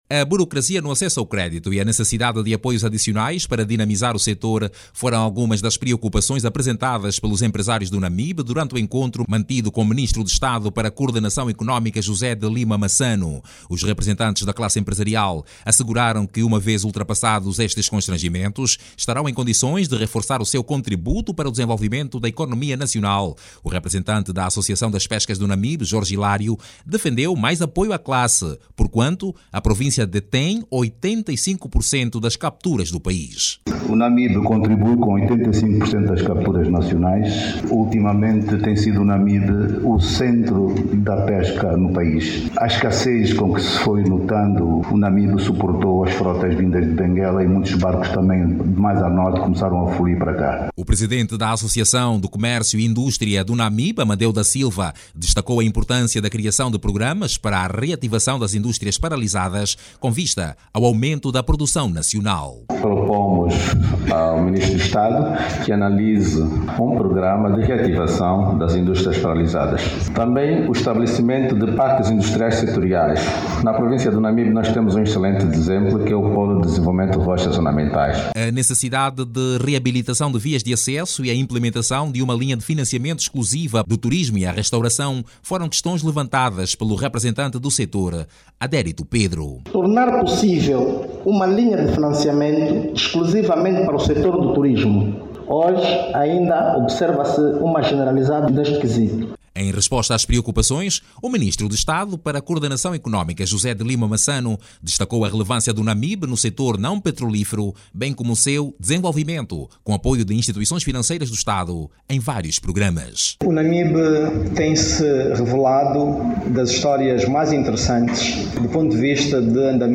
O excesso de burocracia no acesso ao crédito, mau estado das vias de acesso ao campo de produção, estão a dificultar a actividade dos empresários da província do Namibe. Estas dificuldades, foram apresentadas ao Ministro de Estado para Coordenação Económica, José de Lima Massano durante o encontro mantido com a classe empresarial do Namibe. Clique no áudio abaixo e ouça a reportagem